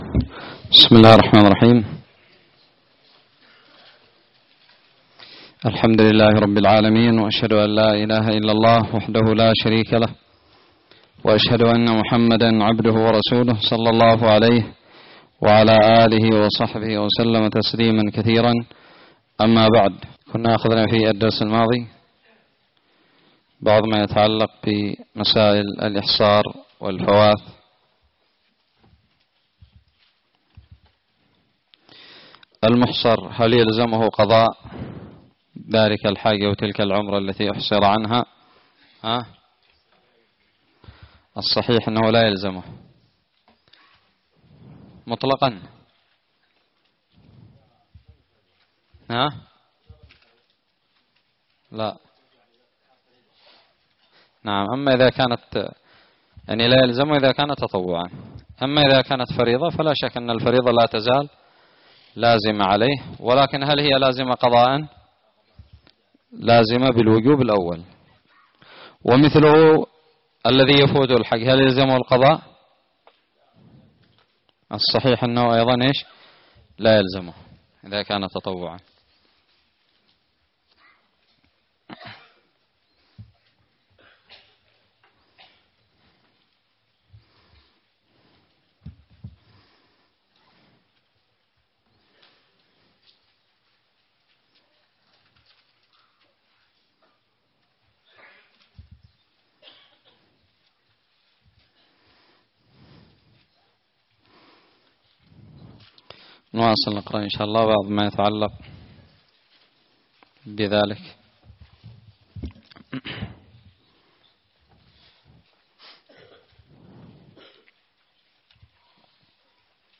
الدرس الثالث والثمانون والأخير من كتاب الحج من الدراري
ألقيت بدار الحديث السلفية للعلوم الشرعية بالضالع